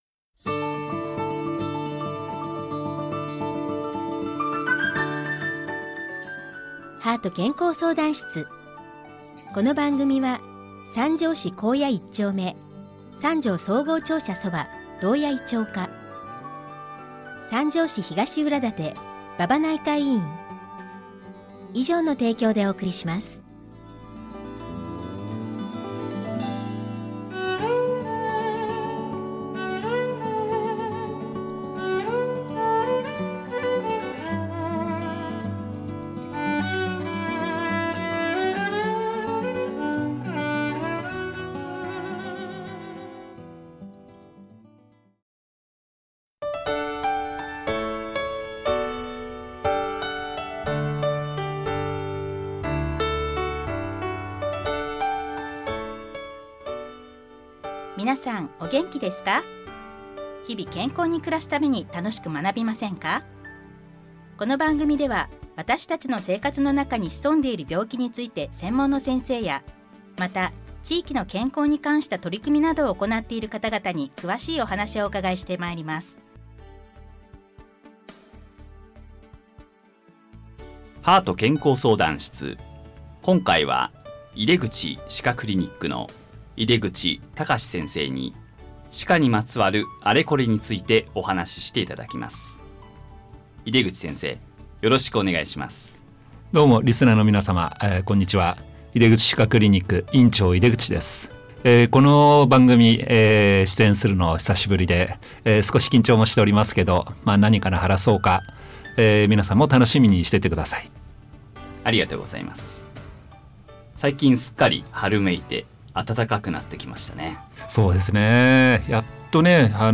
令和7年5月インタビュー 令和7年5月のテーマは 「歯科にまつわるあれこれ」です。